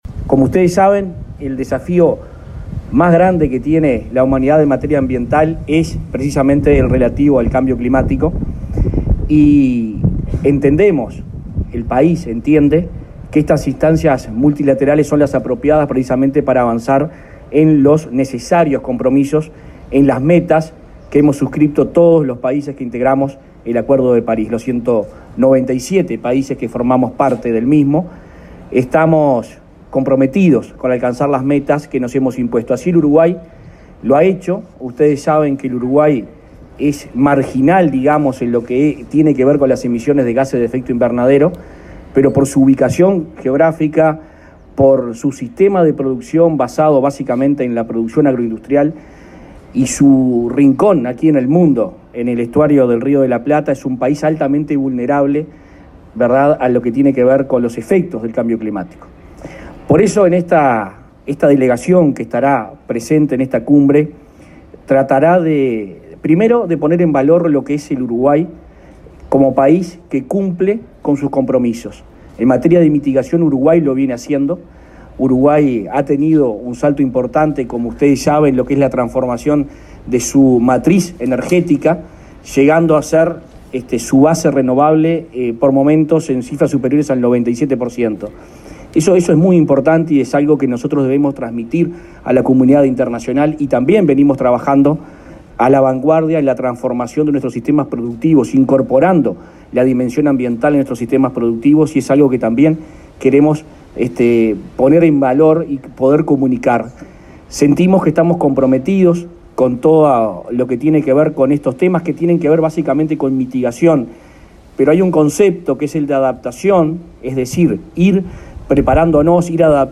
Palabras de los ministros de Ambiente y de Economía y Finanzas
Palabras de los ministros de Ambiente y de Economía y Finanzas 25/10/2021 Compartir Facebook Twitter Copiar enlace WhatsApp LinkedIn El ministro de Ambiente, Adrián Peña, y su par de Economía y Finanzas, Azucena Arbeleche, participaron este lunes 25, en la Embajada Británica, de la presentación de la posición uruguaya en la Conferencia de las Naciones Unidas sobre Cambio Climático, que se realizará entre el 31 de octubre y 12 de noviembre en Glasgow, Escocia.